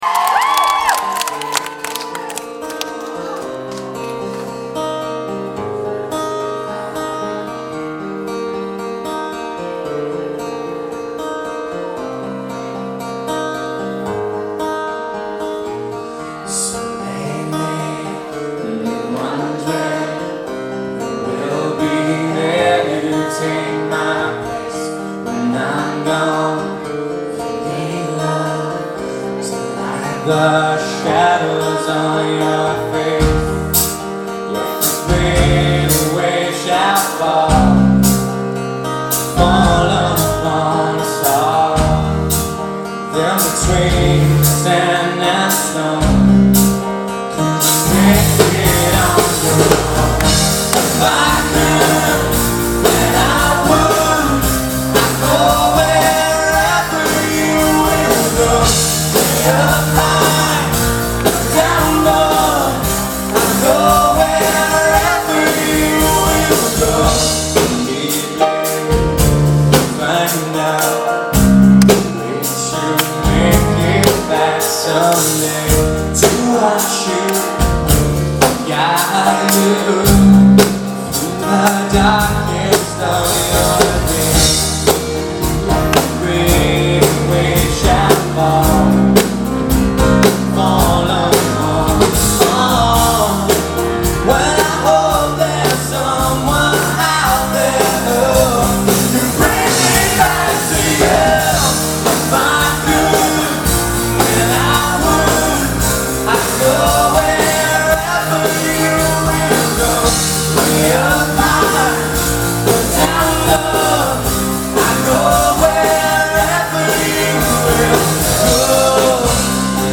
Band Set